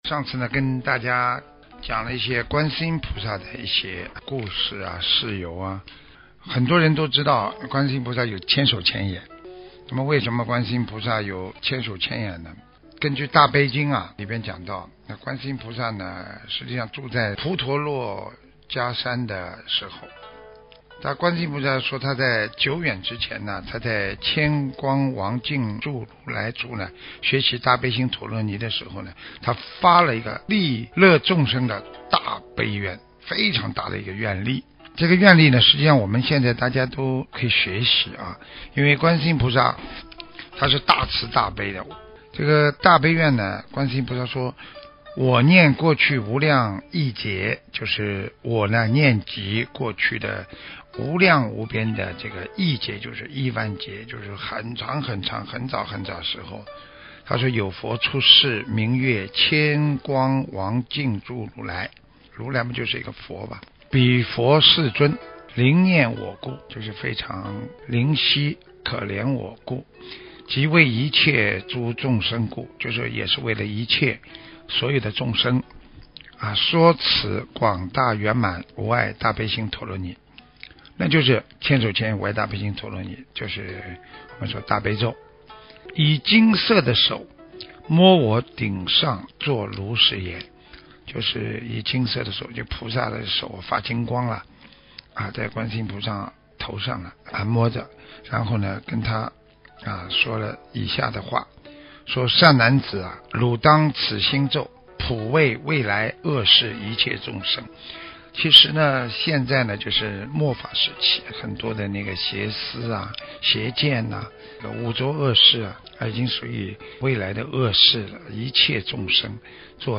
听师父讲观世音菩萨的故事【大合集】